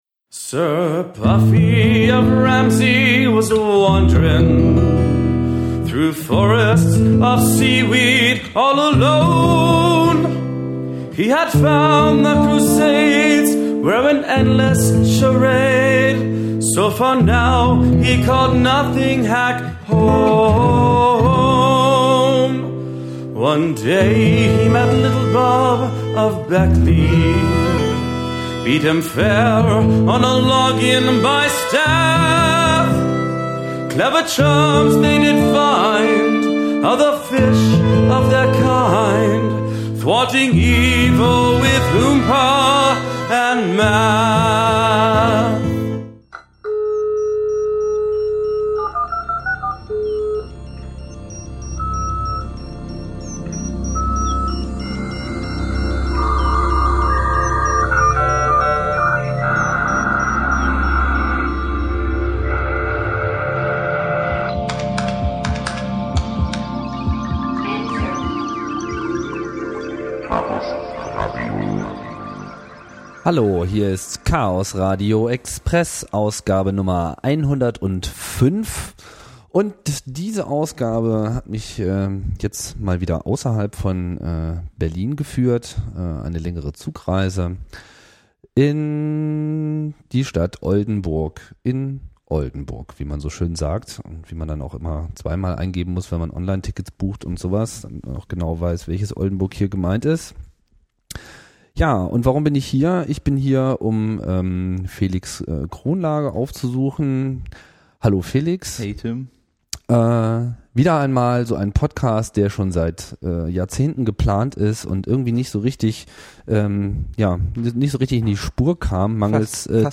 Interview Chaosradio Express 105 - OpenBSD